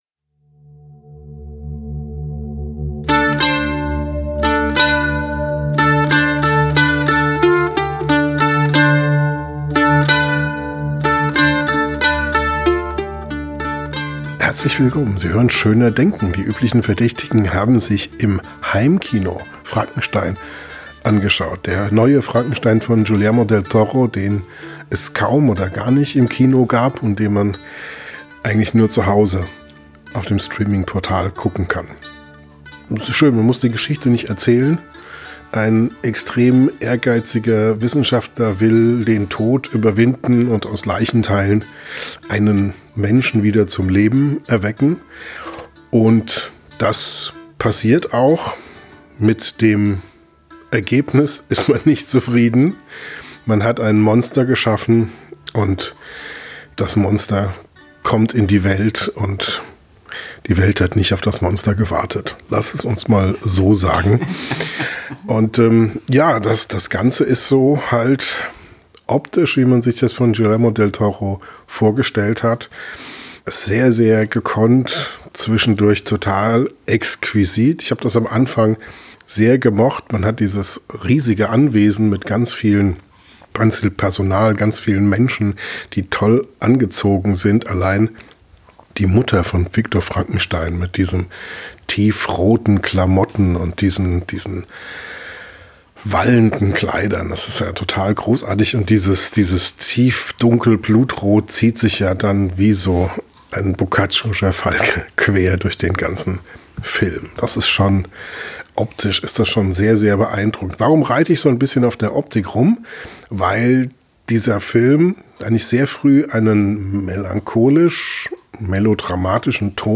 Im Podcast direkt nach dem Film diskutieren wir über die Gründe, warum uns der Film nicht erreicht hat, über zu viele Sonnenuntergänge und zu wenig „echte“ Menschen, über perfektes Filmhandwerk, dröges Erzählen – und über schlecht animierte Wölfe.